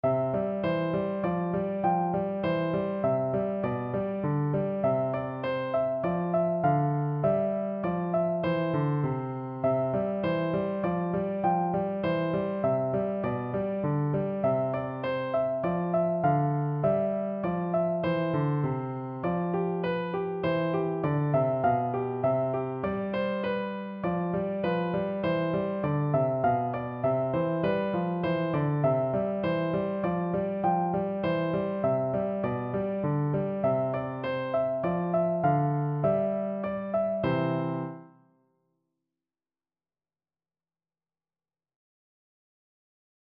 No parts available for this pieces as it is for solo piano.
~ = 100 Moderato
2/4 (View more 2/4 Music)
Classical (View more Classical Piano Music)